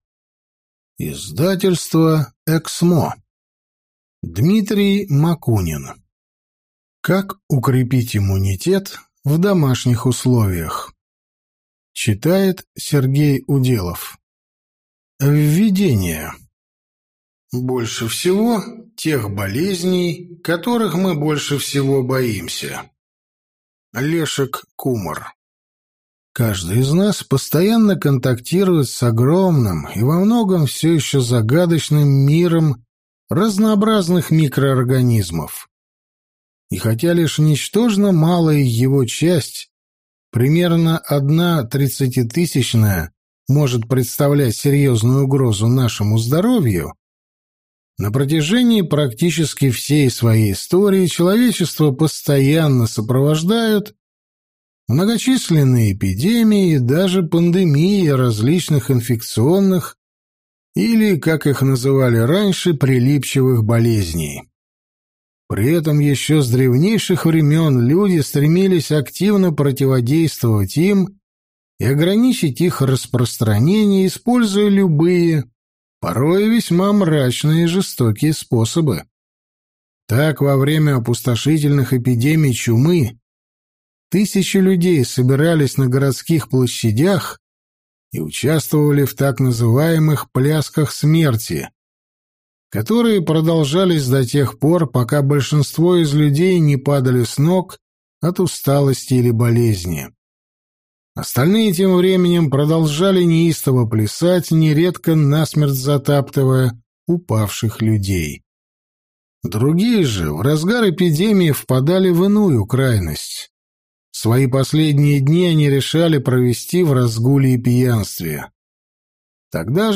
Аудиокнига Как укрепить иммунитет в домашних условиях | Библиотека аудиокниг